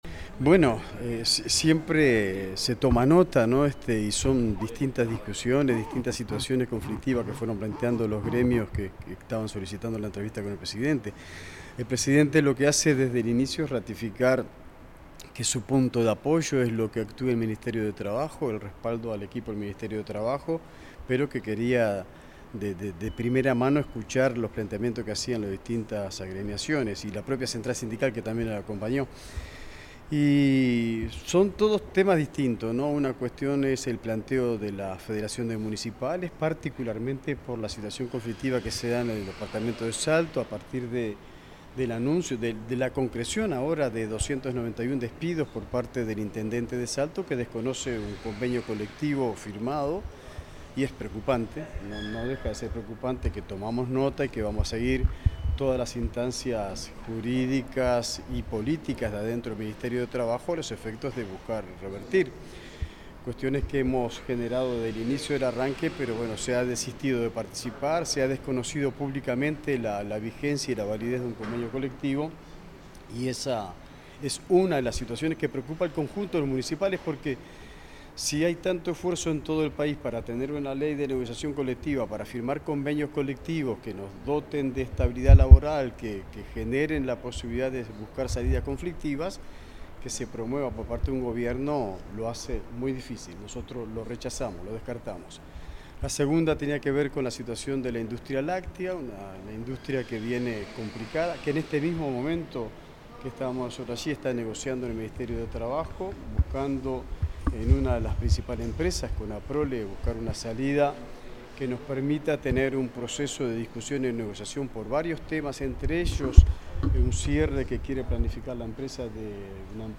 Declaraciones del ministro de Trabajo y Seguridad Social, Juan Castillo
El ministro de Trabajo y Seguridad Social, Juan Castillo, dialogó con la prensa sobre las reuniones mantenidas con el presidente de la República,